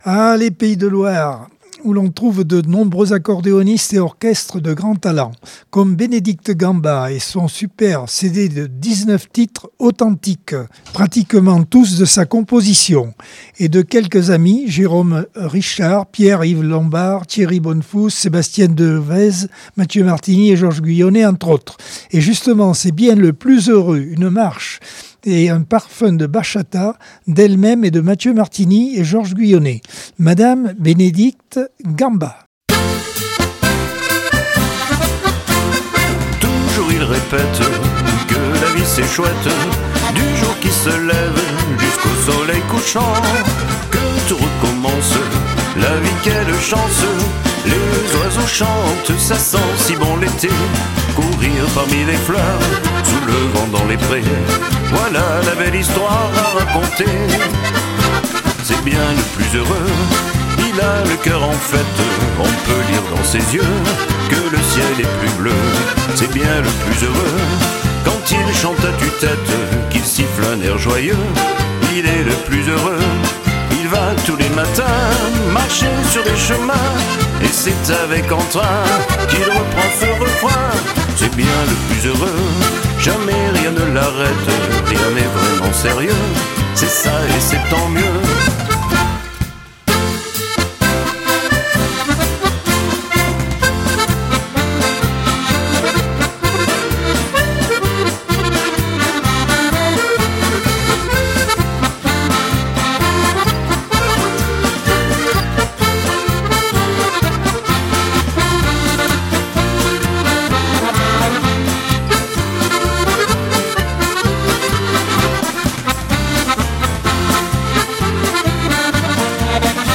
Accordeon 2025 sem 02 bloc 4 - Radio ACX